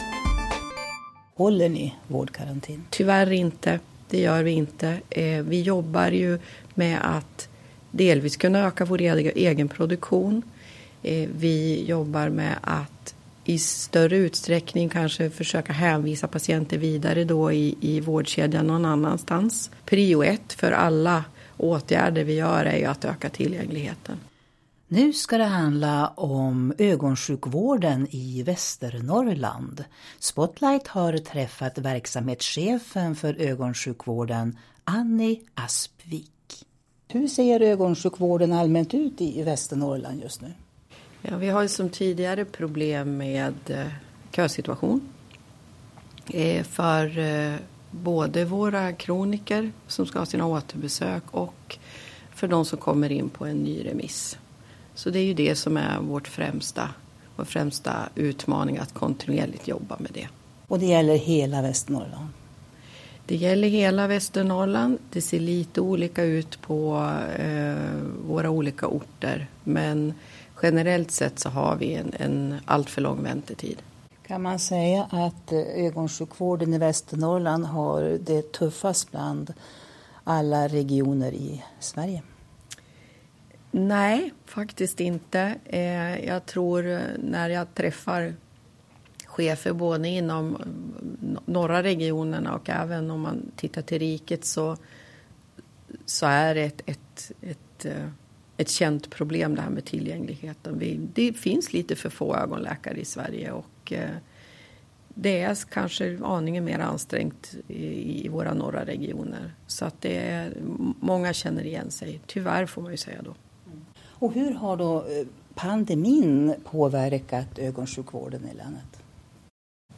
Här intervjuas hon om nuläget för ögonsjukvården i länet och om kommande satsningar för att utveckla och göra den mer tillgänglig.